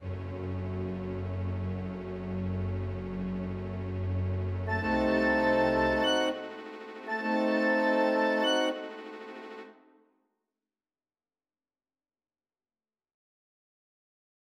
마탄의 사수(오케스트라만)의 늑대 협곡 음악